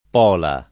Pola [ p 0 la ] top. (Istria)